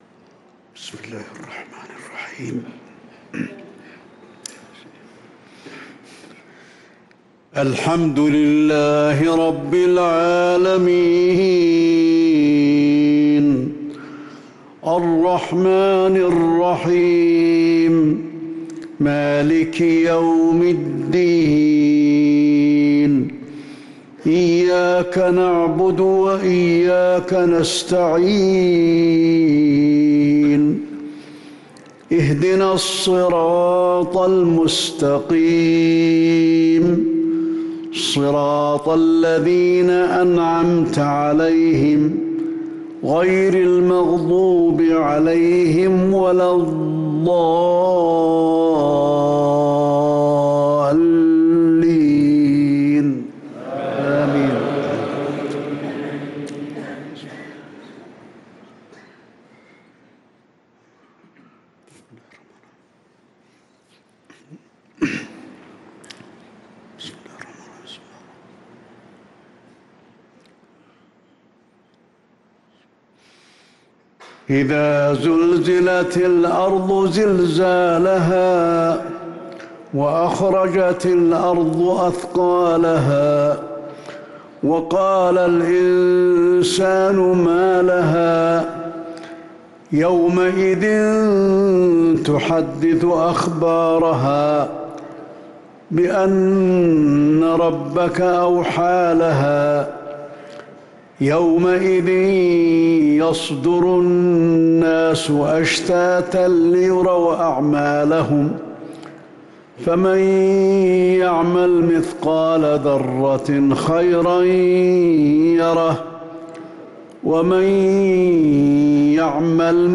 صلاة المغرب للقارئ علي الحذيفي 21 جمادي الأول 1445 هـ
تِلَاوَات الْحَرَمَيْن .